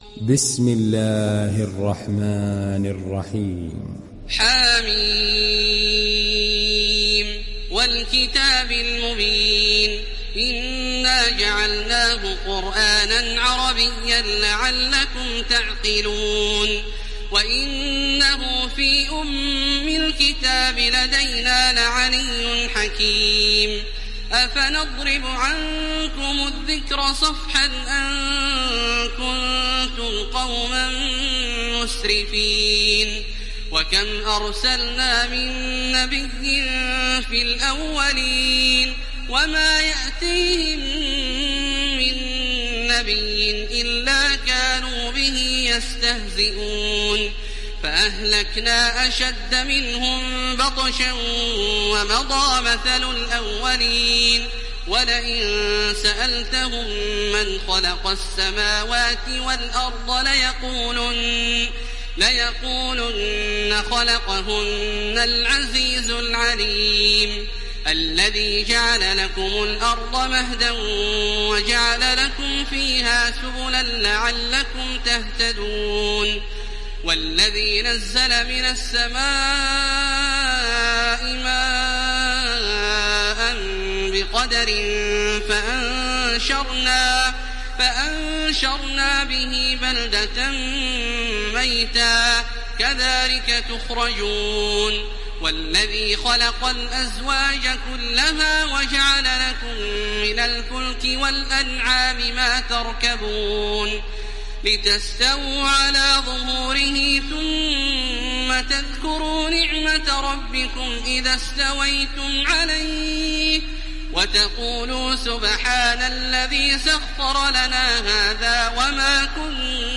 دانلود سوره الزخرف mp3 تراويح الحرم المكي 1430 روایت حفص از عاصم, قرآن را دانلود کنید و گوش کن mp3 ، لینک مستقیم کامل
دانلود سوره الزخرف تراويح الحرم المكي 1430